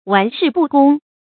注音：ㄨㄢˊ ㄕㄧˋ ㄅㄨˋ ㄍㄨㄙ
讀音讀法：
玩世不恭的讀法